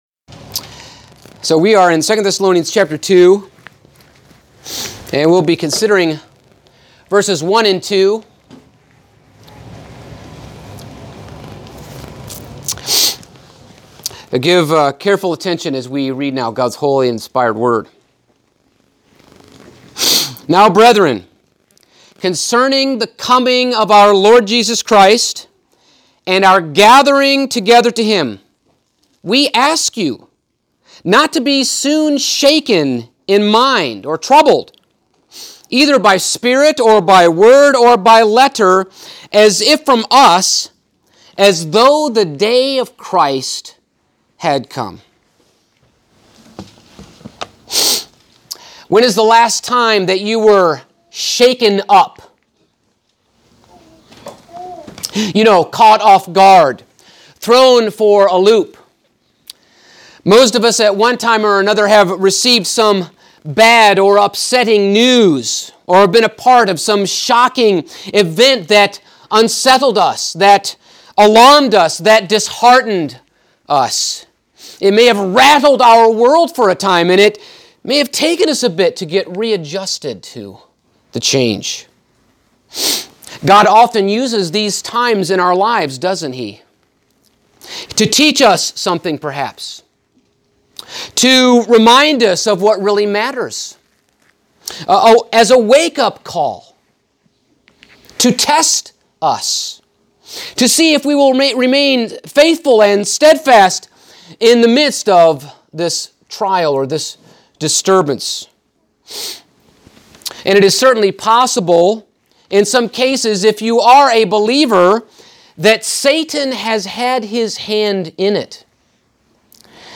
Passage: 2 Thessalonians 2:1-2 Service Type: Sunday Morning